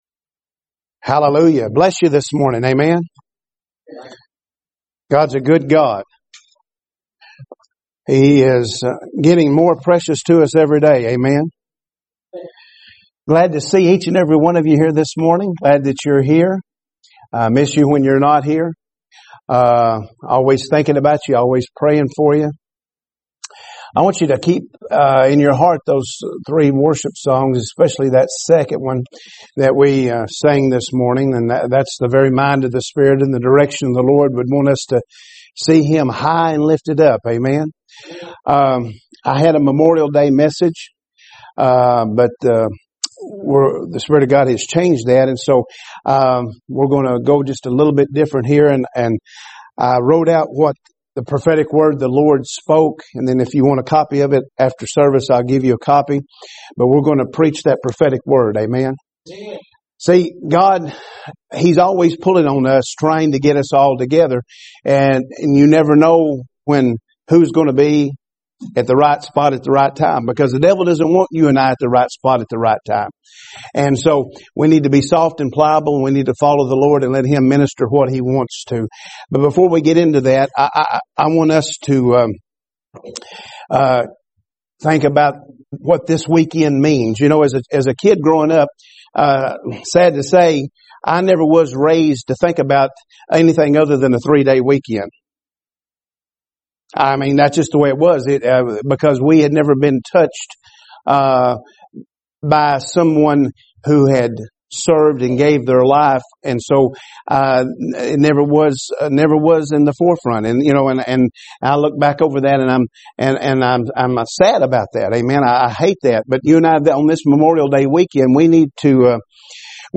Sermons – Page 11 – Victory Worship Center